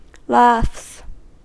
Ääntäminen
Ääntäminen US Tuntematon aksentti: IPA : /lɑːfs/ Haettu sana löytyi näillä lähdekielillä: englanti Käännöksiä ei löytynyt valitulle kohdekielelle. Laughs on sanan laugh monikko.